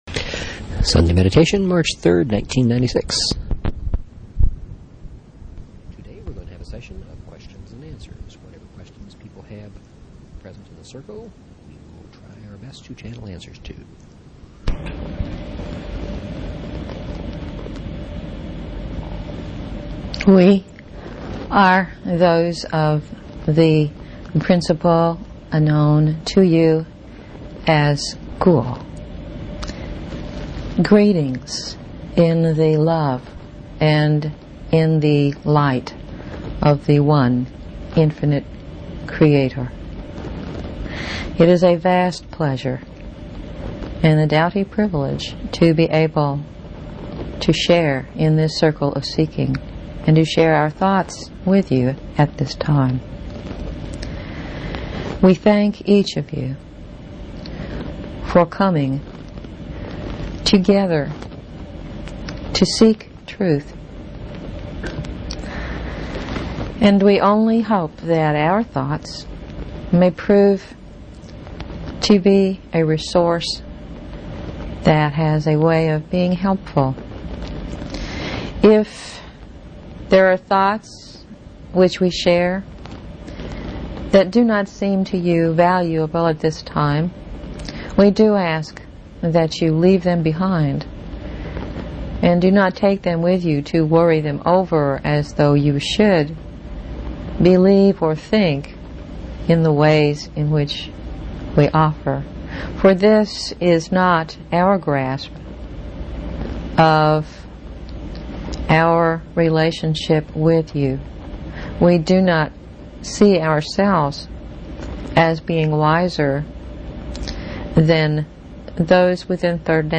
Show Headline LLResearch_Quo_Communications Show Sub Headline Courtesy of BBS Radio March 3, 1996 Today we will have a session of questions and answers from those in the circle.